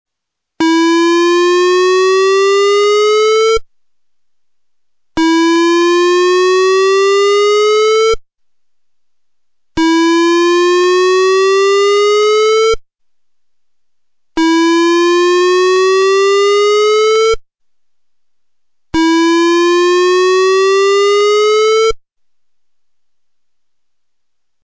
The system is designed to provide audible public warning signals (tone) and voice messages within a range of approximately one mile (5,200 ft.) from the center of Telford Borough in all directions.
1. ‘Whoop’ tone (Other Public Emergency – Shelter in place)
whoop.mp3